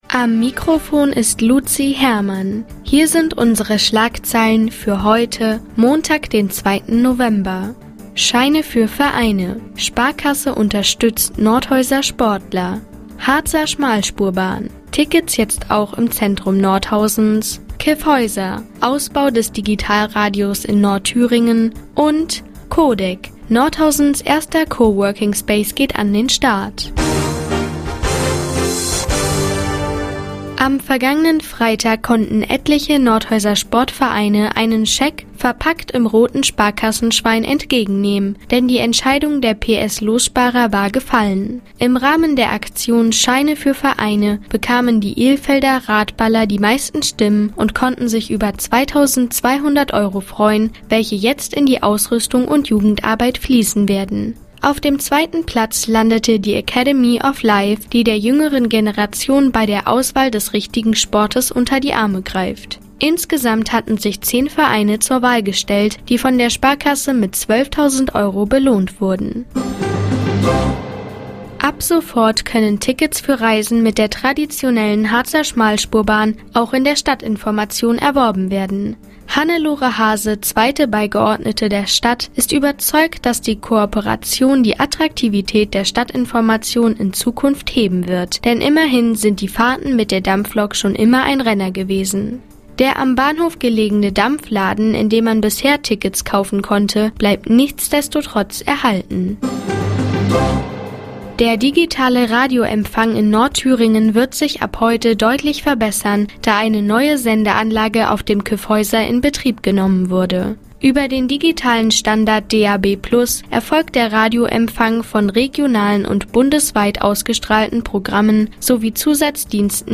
Die tägliche Nachrichtensendung des OKN ist jetzt hier zu hören...